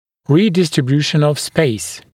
[ˌriːdɪstrɪ’bjuːʃn əv speɪs][ˌри:дистри’бйу:шн ов спэйс]перераспределение места